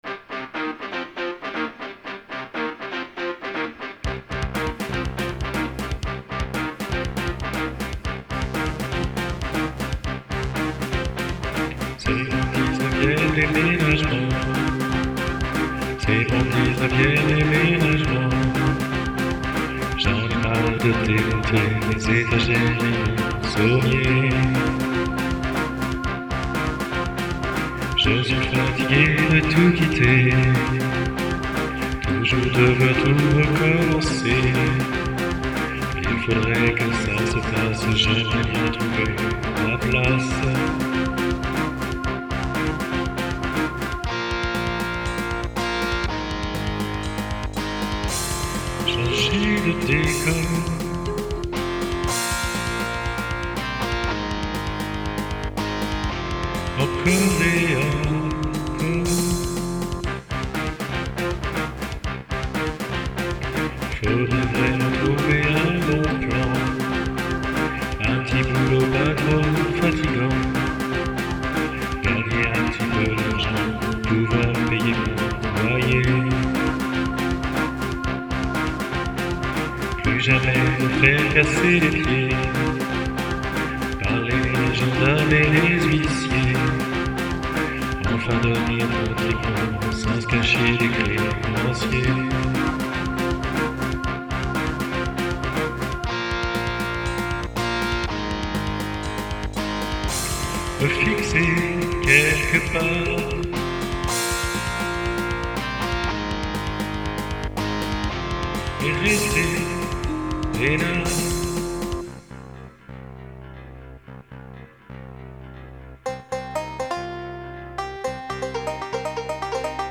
Chanson en français, genre Hard Rock.